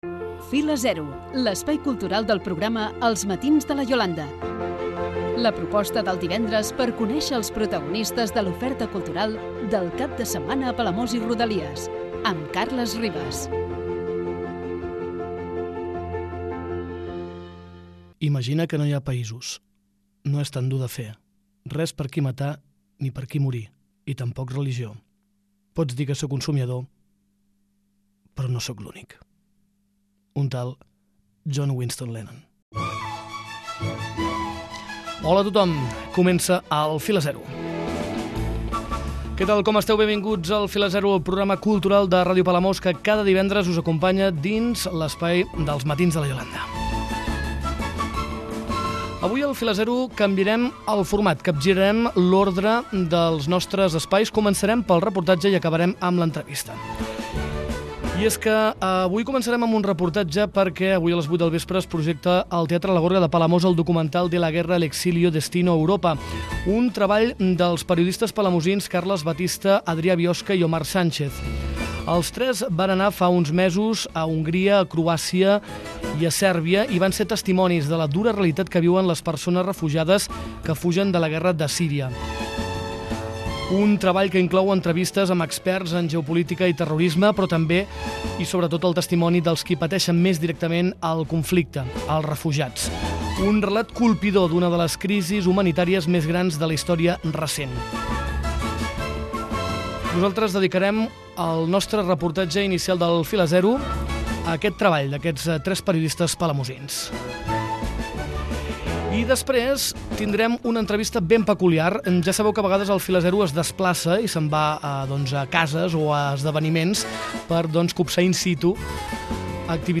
És un treball periodístic realitzat per tres joves palamosins que han viatjat als camps de refugiats de Croàcia, Sèrbia i Hongria. En el reportatge del "Fila Zero" recollim les declaracions dels autors del documental, així com petits fragments del documental. A la part final del programa us oferim una entrevista feta a l'Exercit d'Alliberament Musical després d'un concert realitzat la passada setmana a l'Inagua del Port Marina.